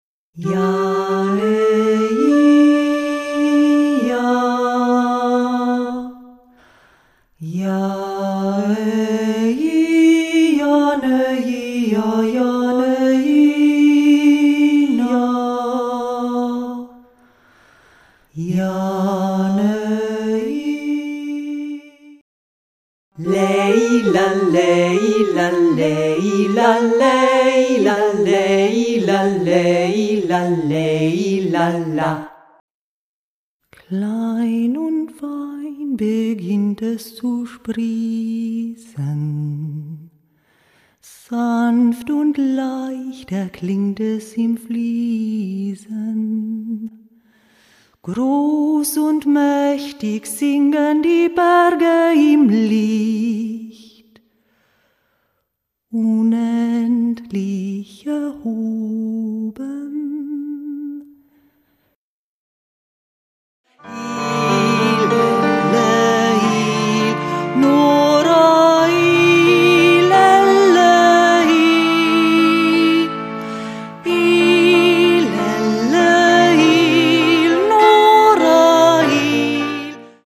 (Instrumentalversion)